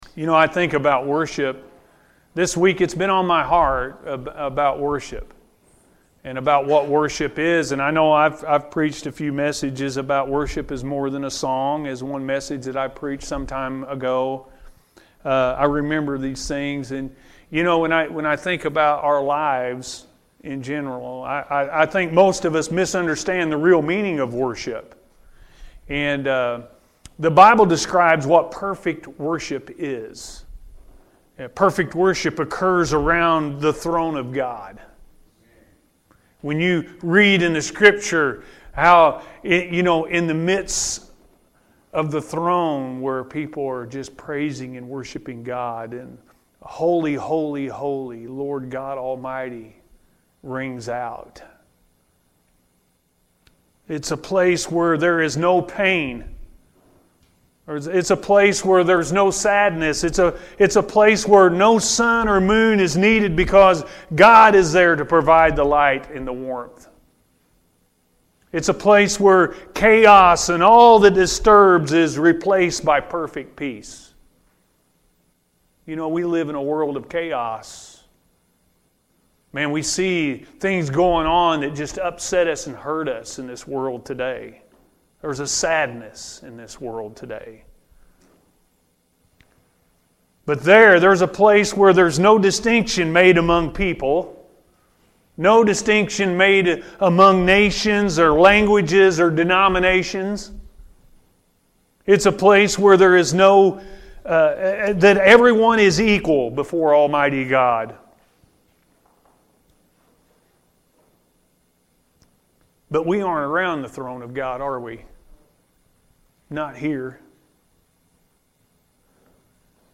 True Worship Is All About God-A.M. Service – Anna First Church of the Nazarene